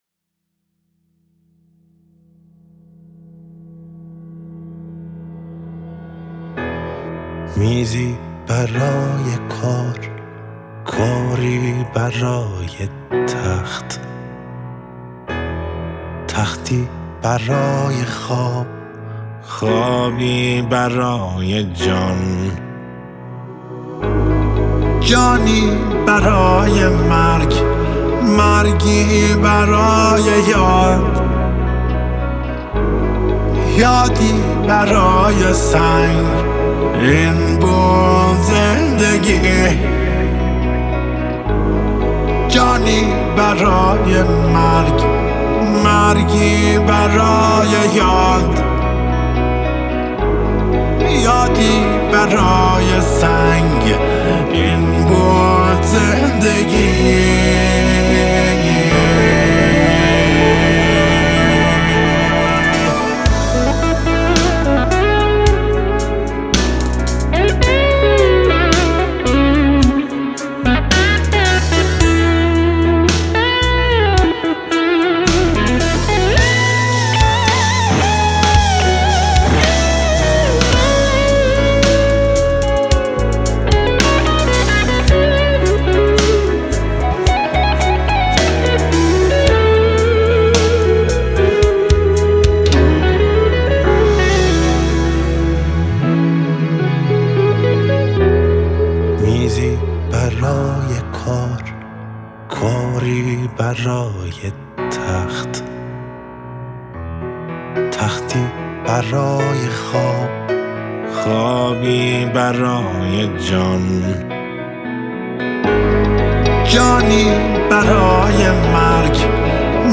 گیتار الکتریک